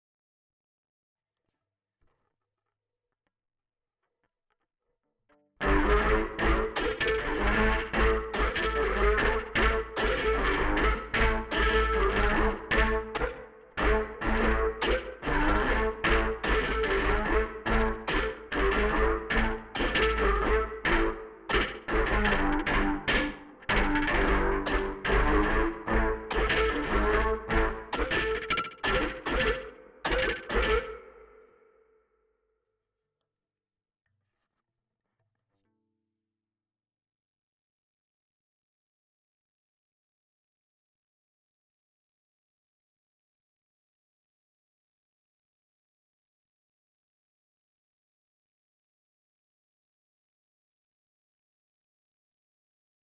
I’m working on some patches in AUM to create a very rhythmic “bass - treble” groove. I am bowing the bass notes, and then percussively chopping the top 2 treble strings.
THE SOUND I’M AFTER: I want the low bass notes to be something like an 80s bass synth.